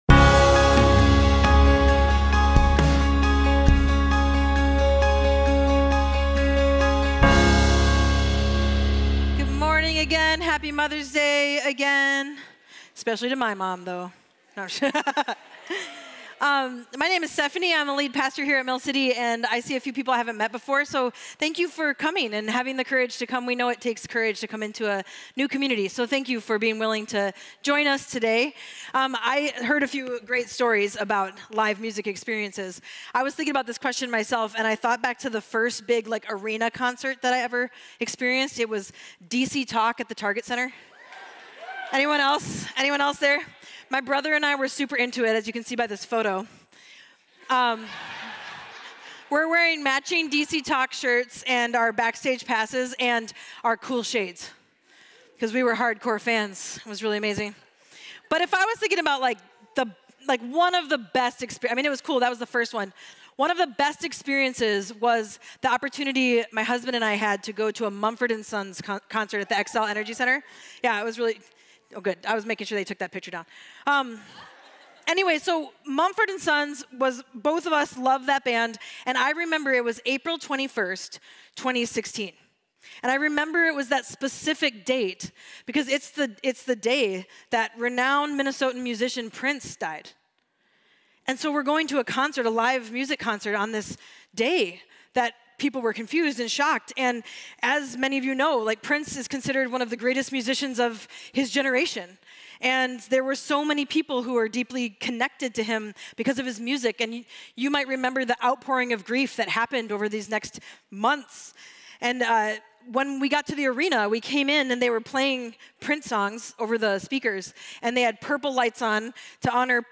Mill City Church Sermons Relating: Putting Joy into Practice May 13 2024 | 00:35:58 1x 00:00 / 00:35:58 Subscribe Share RSS Feed Share Link Embed